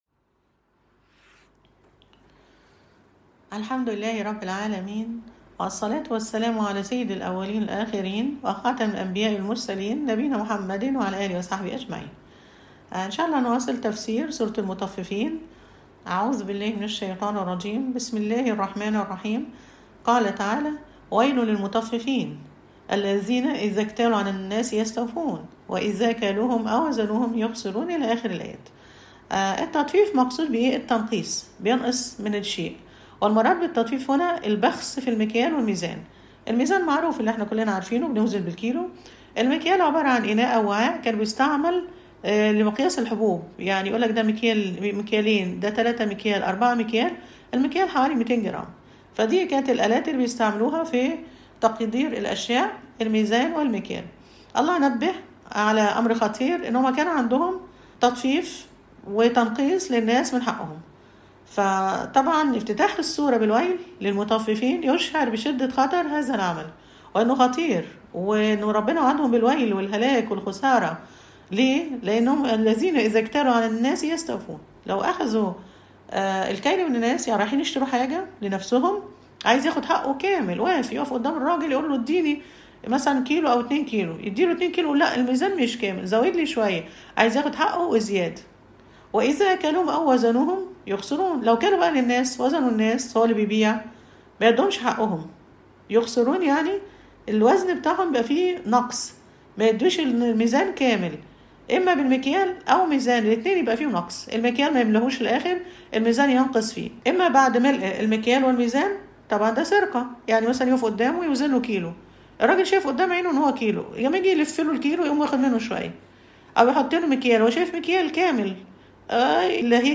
المحاضرة السابعة_ سورة المطففين